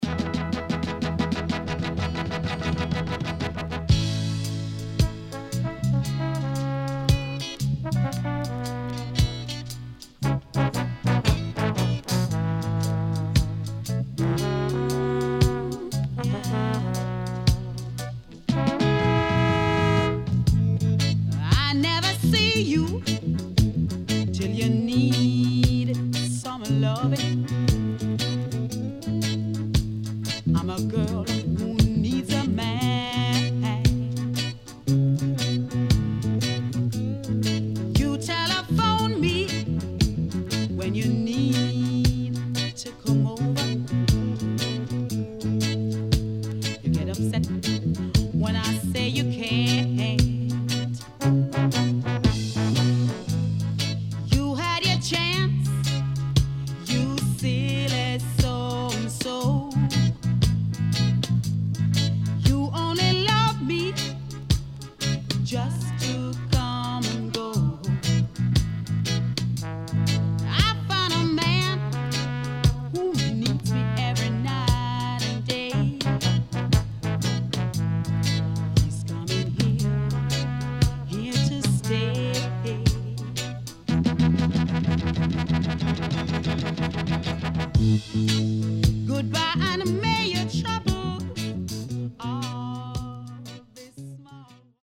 HOME > Back Order [LOVERS]  >  SWEET REGGAE
SIDE A:少しチリノイズ入りますが良好です。